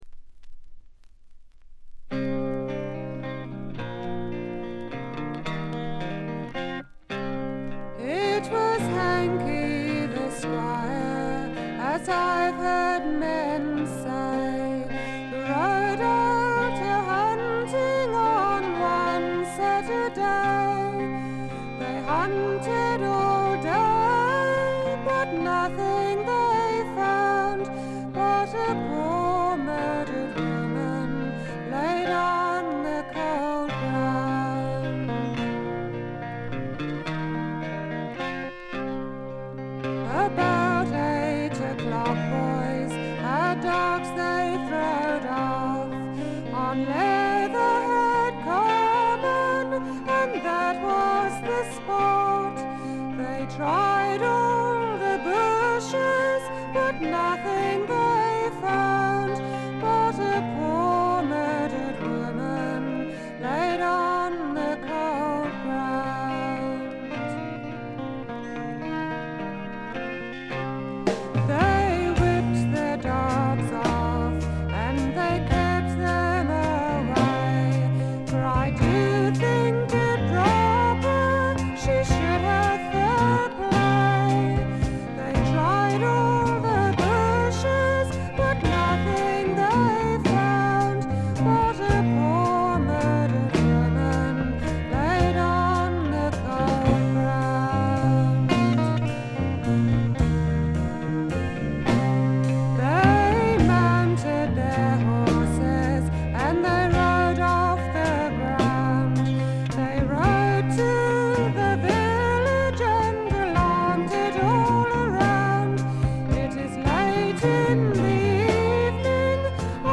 ごくわずかなノイズ感のみ。
エレクトリック・トラッド最高峰の一枚。
試聴曲は現品からの取り込み音源です。